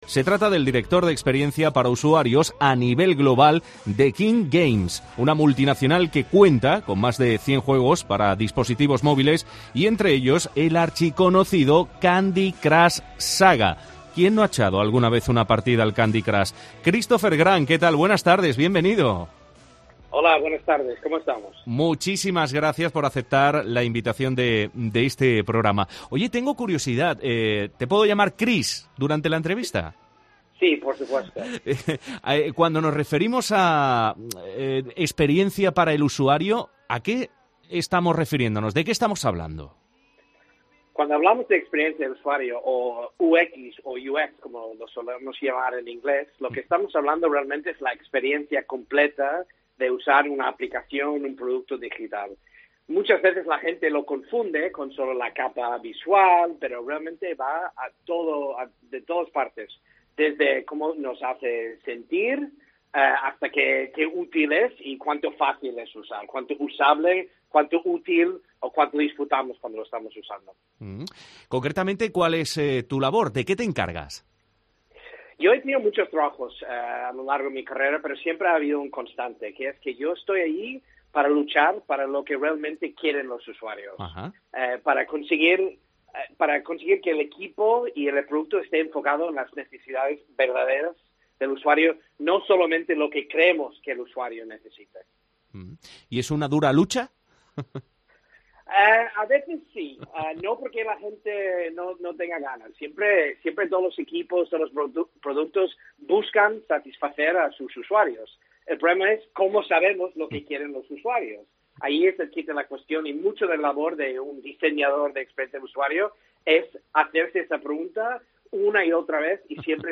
COPE Málaga entrevista a uno de los directivos de King Games, estudio creador del exitoso juego Candy Crush Saga